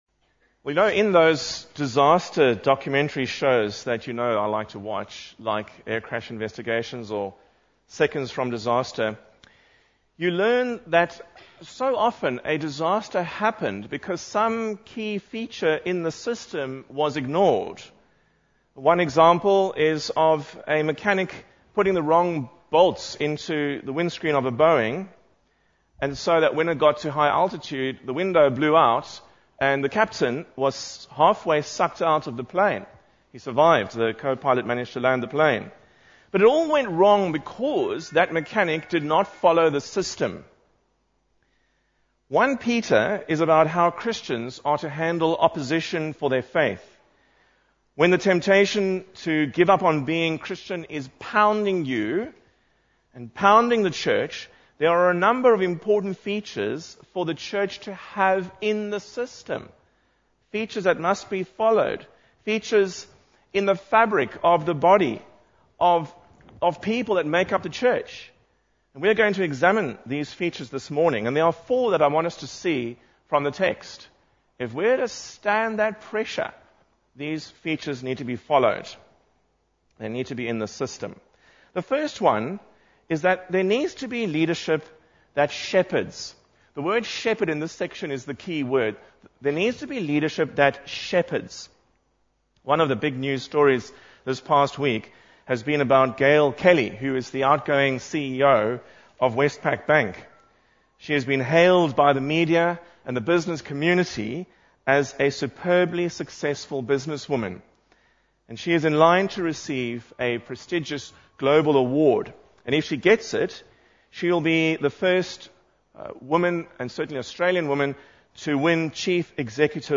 1 Peter 5:1-14 Service Type: Morning Service Bible Text